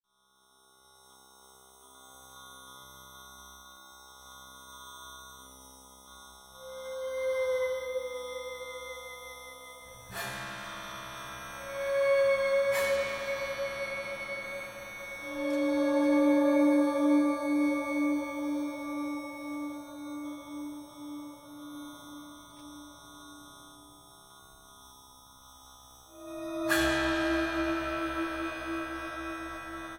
Free improvisation that stretches the limits